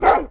bark3.wav